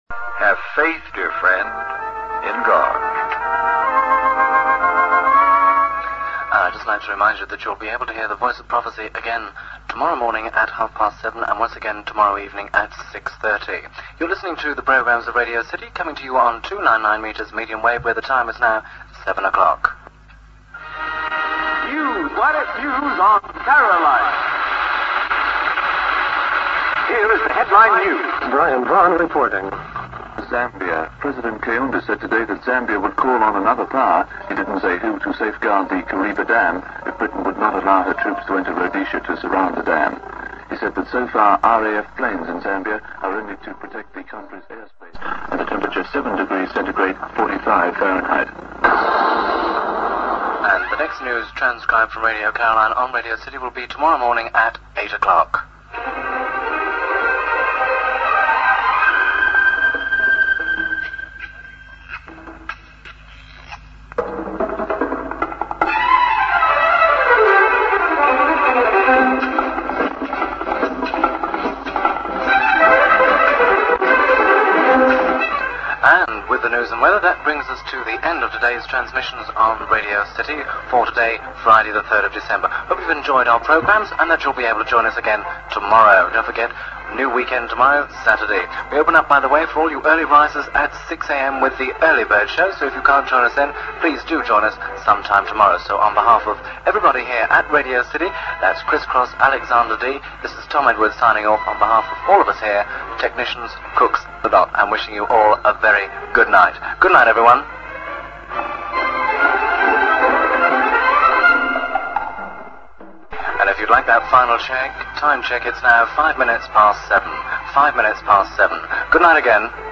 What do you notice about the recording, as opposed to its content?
For the listeners, the only noticeable signs of this collaboration were that Radio City began to re-broadcast Caroline's news, recorded off air and transmitted an hour later, and occasionally plugged some of the bigger station's programmes.